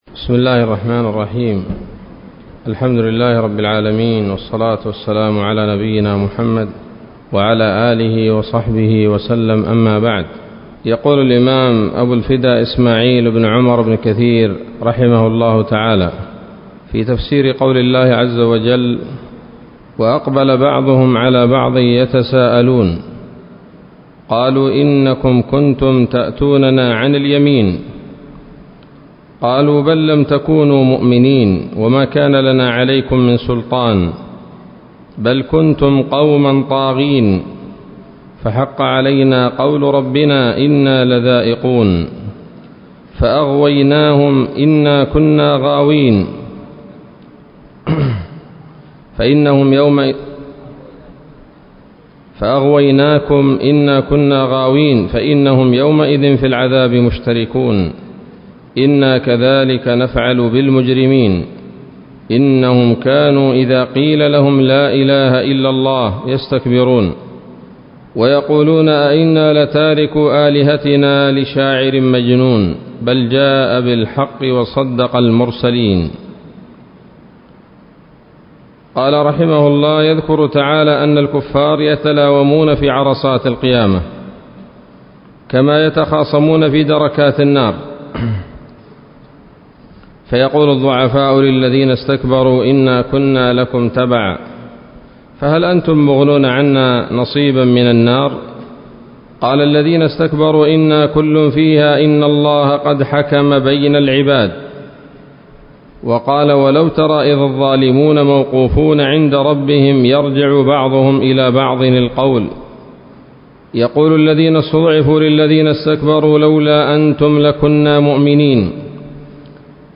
الدرس الثالث من سورة الصافات من تفسير ابن كثير رحمه الله تعالى